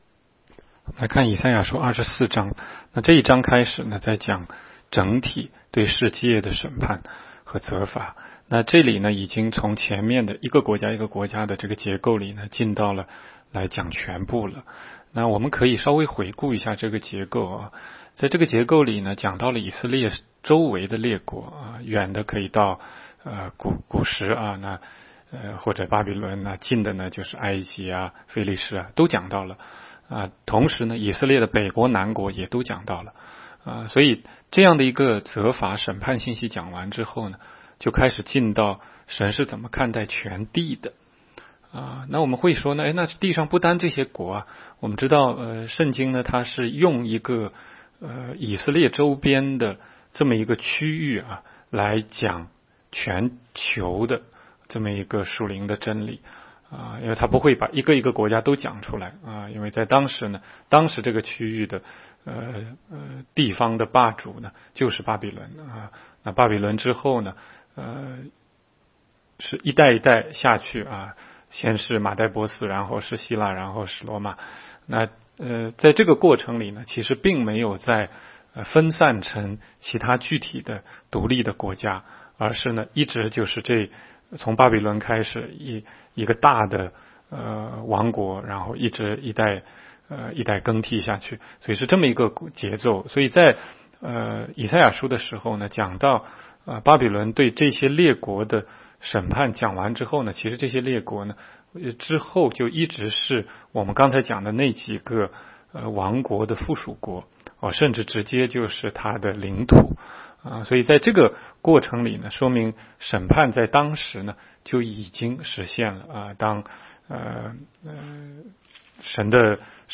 16街讲道录音 - 每日读经 -《 以赛亚书》24章